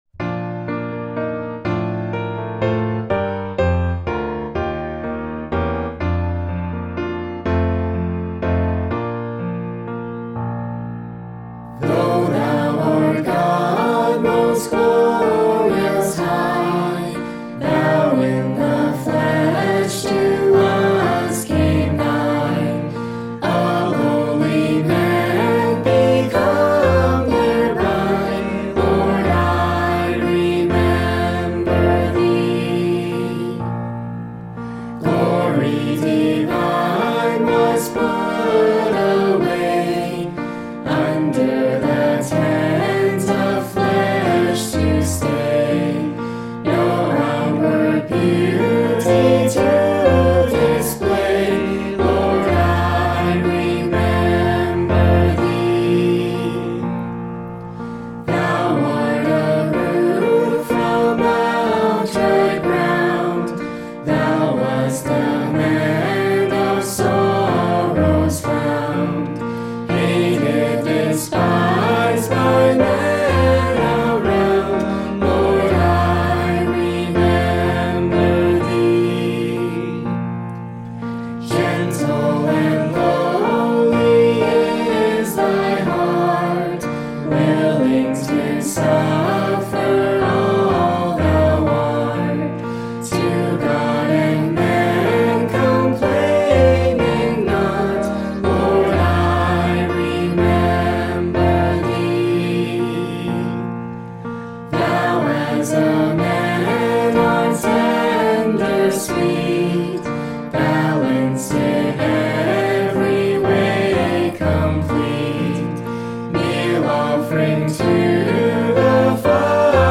Bb Major
e0086_harmony.mp3